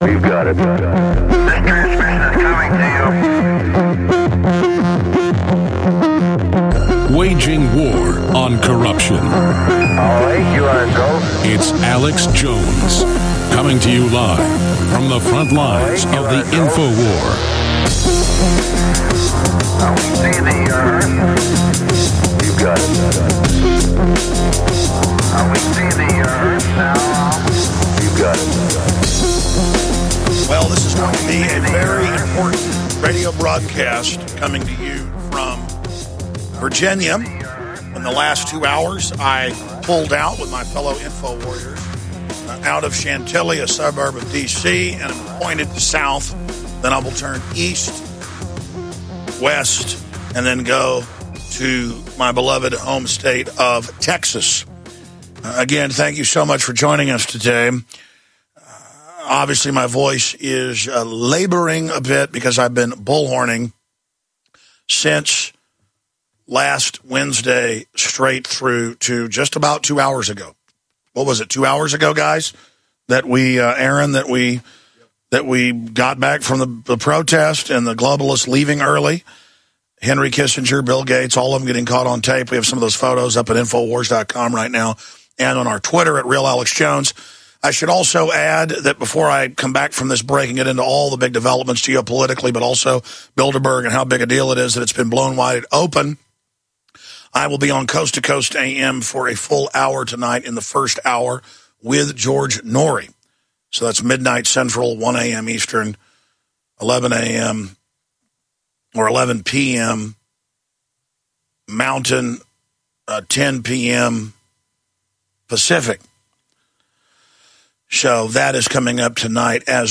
Alex Jones Show Commercial Free Podcast
Watch Alex's live TV/Radio broadcast.